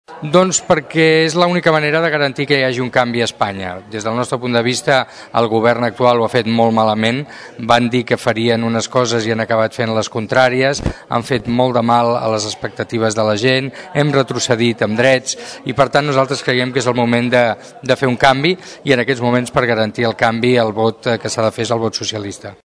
Prèviament, en declaracions en aquesta emissora, Iceta va demanar el vot pels socialistes assegurant que és l’única manera que hi hagi un canvi.